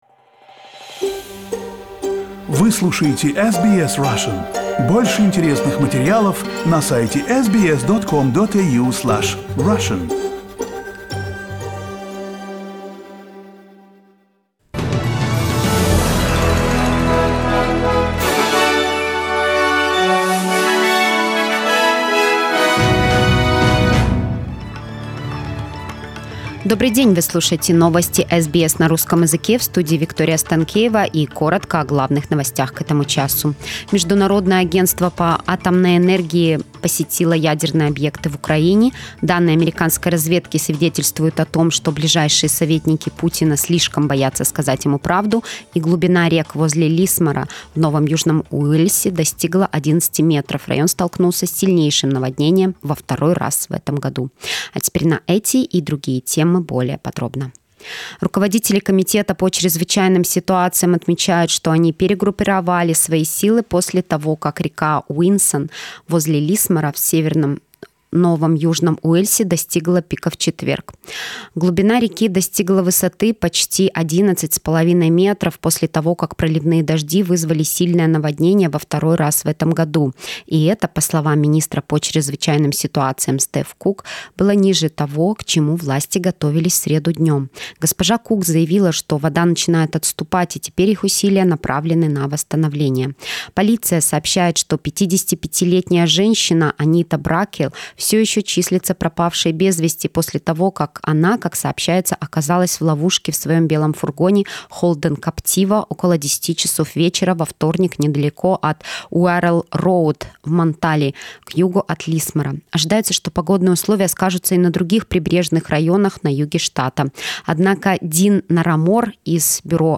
SBS news in Russian - 31.03